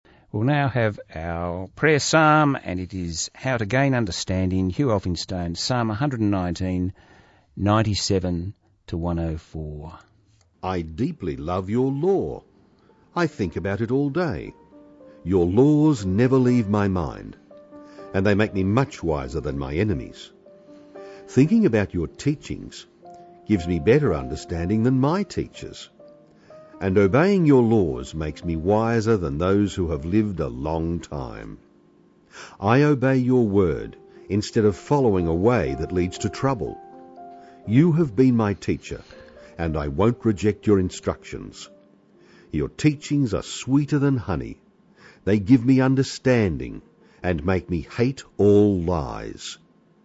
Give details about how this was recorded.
It is Psalm 119 verses 97 to 104. This was broadcast on Sunday 1 December 2013 on Songs of Hope on Southern FM 88.3.